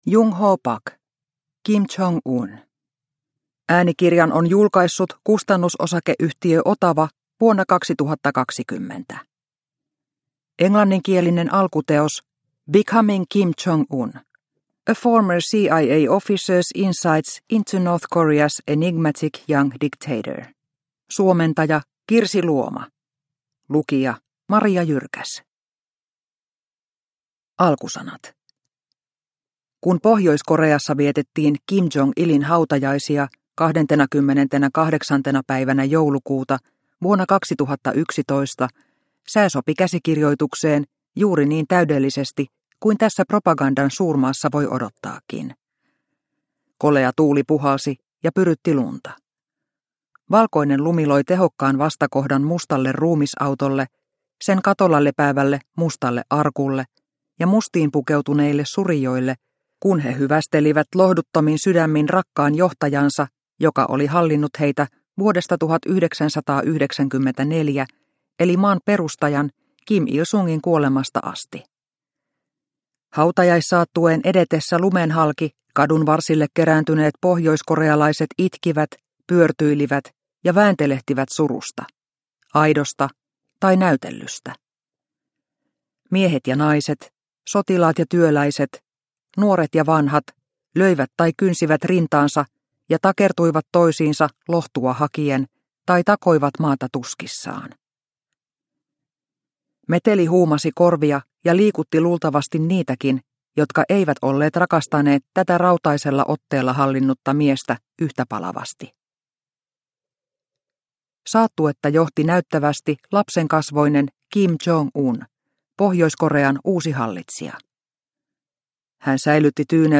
Kim Jong Un – Ljudbok – Laddas ner